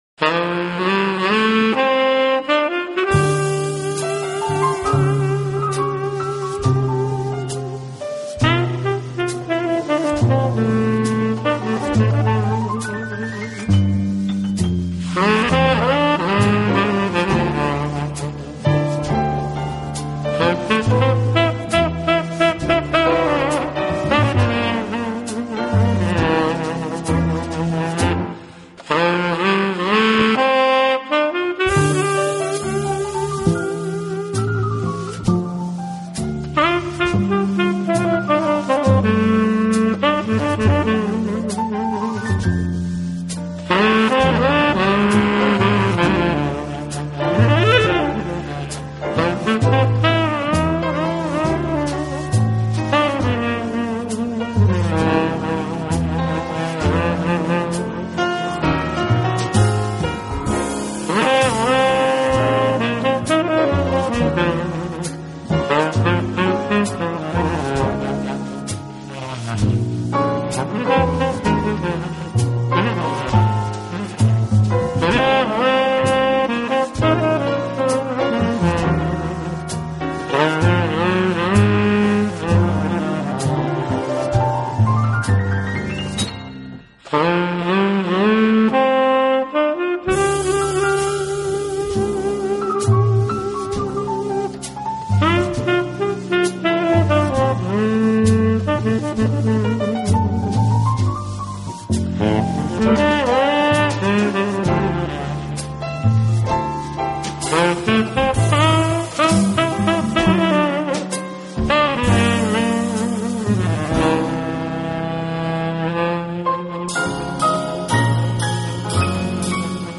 轻快、柔和、优美，带有浓郁的爵士风味。